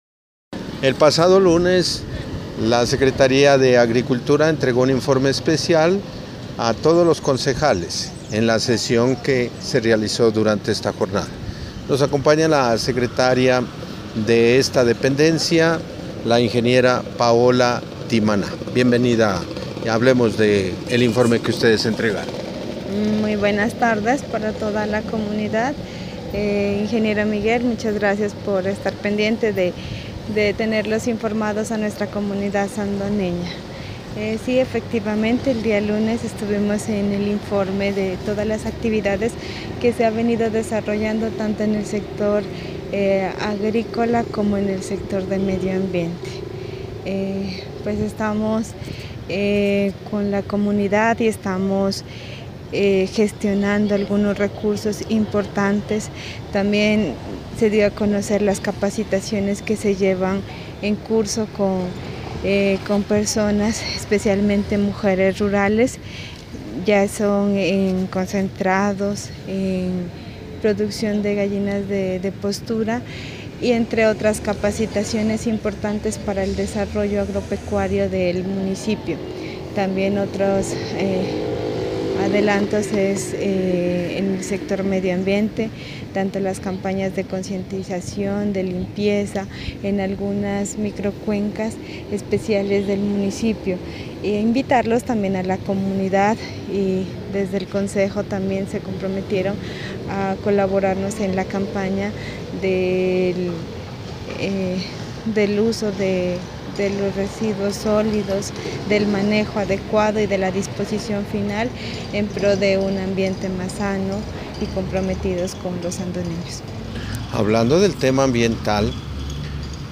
Entrevista con la secretaria de agricultura Paola Timaná Maya.